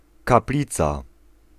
Ääntäminen
Synonyymit basilique Ääntäminen France, région parisienne: IPA: [ʃa.pɛl] Haettu sana löytyi näillä lähdekielillä: ranska Käännös Konteksti Ääninäyte Substantiivit 1. kaplica {f} uskonto Suku: f .